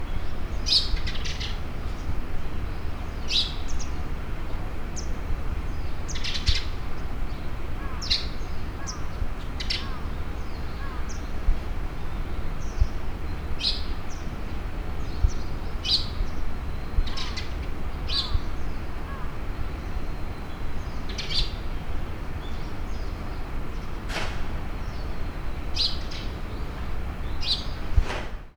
まずはこれに単3電池×4本を入れて、サンプリングレートは96kHzで32bitフロートに設定したうえで、屋外に持ち出してみた。
朝近所を歩いて、ちょうど真上の電線に留まって鳴いていたスズメを捉えたのが下の音声データ。
この録音時において、入力ゲインは適当な設定だったため、その後、波形編集ソフトであるSound Forgeに取り込んで調整した上で、24bit/96kHzとして保存した形になっている。
録音サンプル「鳥」
H5studio_bird2496.wav